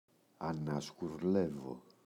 ανασγουρλεύω [anazγu’rlevo]